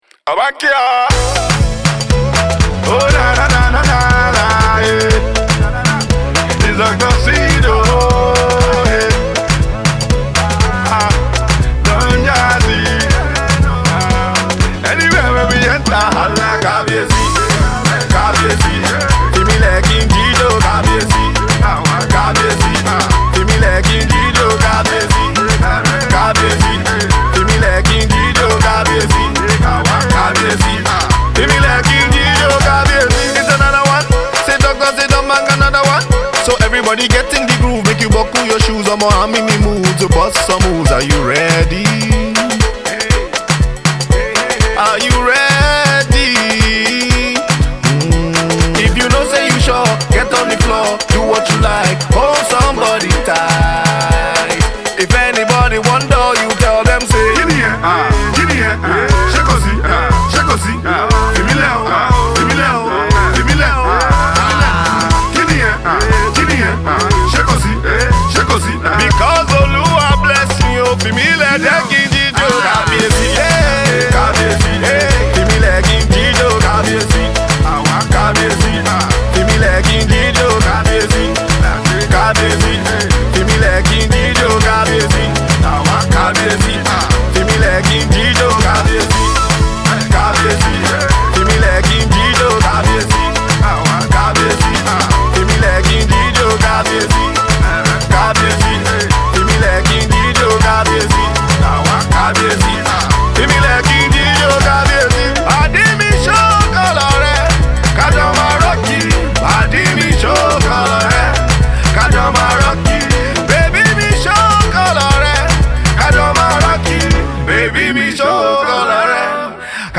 banging tune